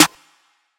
Snare [90210].wav